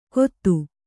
♪ kottu